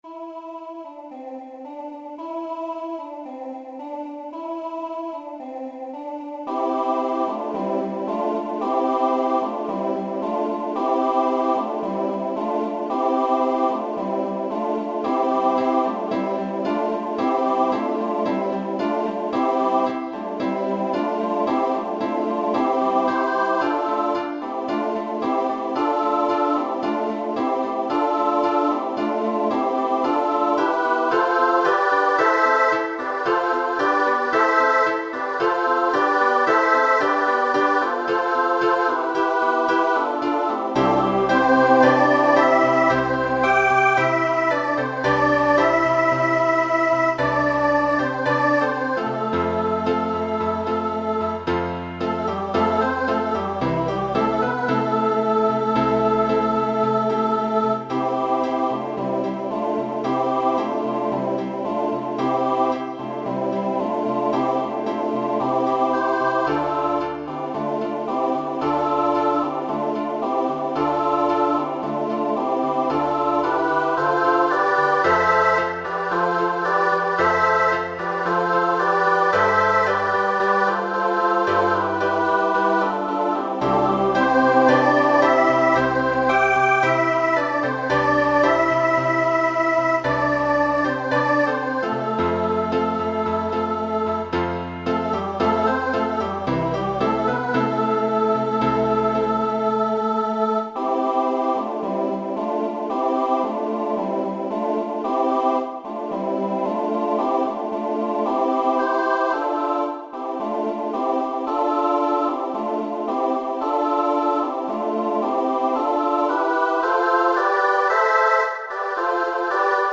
SSA choir, with piano, guitar and tambourine Original